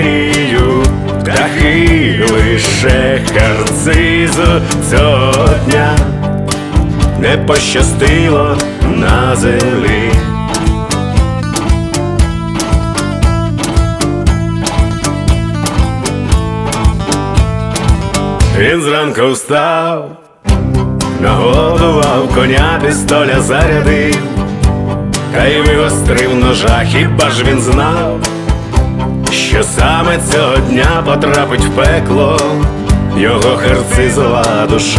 Жанр: Фолк-рок / Украинские